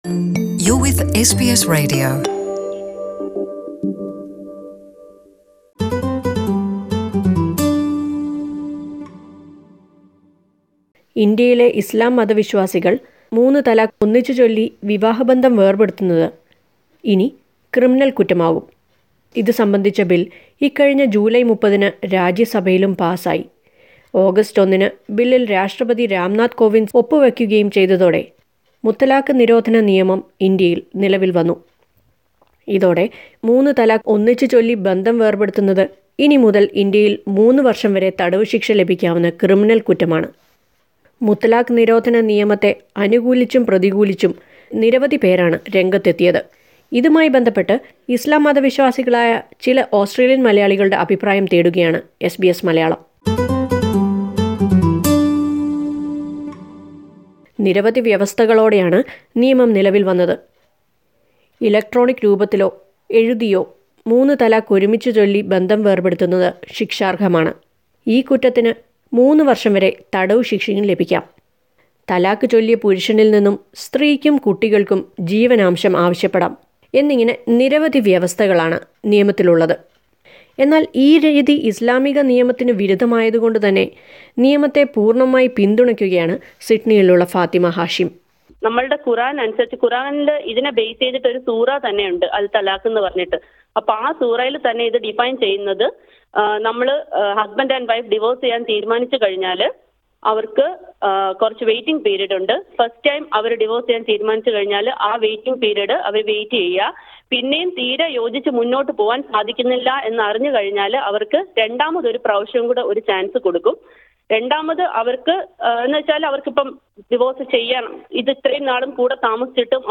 What do Australian Malayalee Islam believers think about the new muthalaq ban in India? Listen to a report